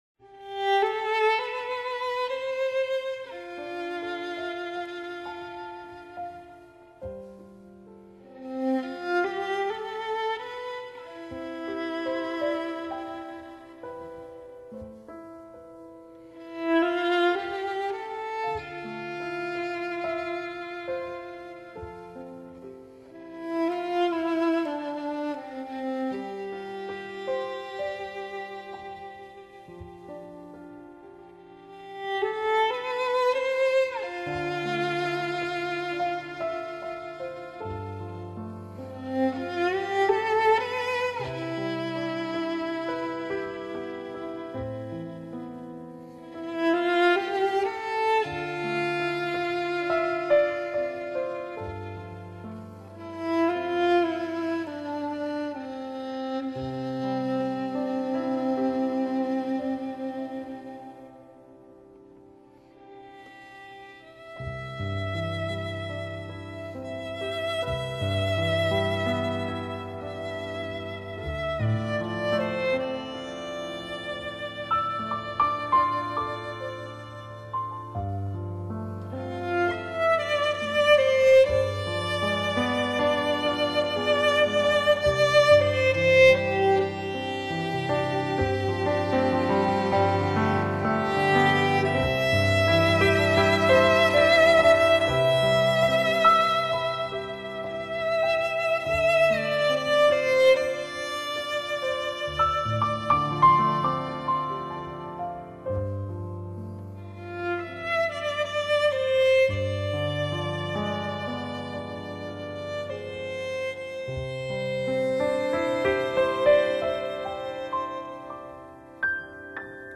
音乐的女神踏着优雅的旋律行于夜色中，所到处散发着冬日箐火般的温暧。